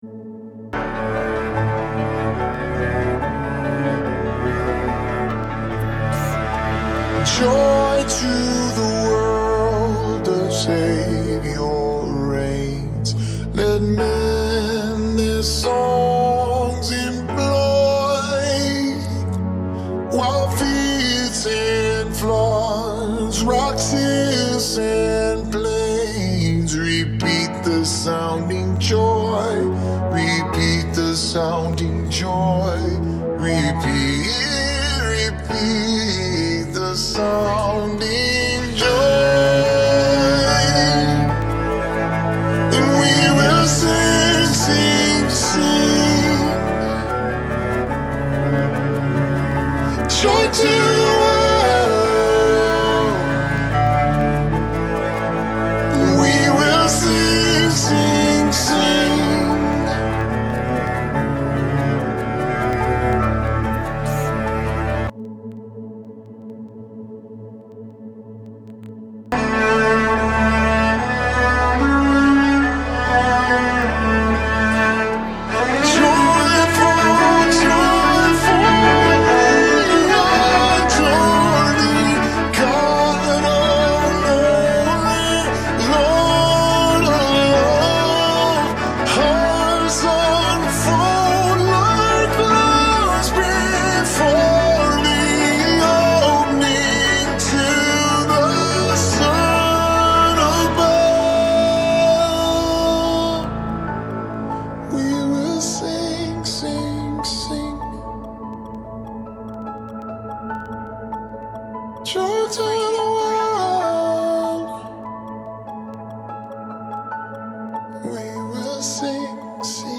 Harmony:
Joy-To-The-World-Choir.mp3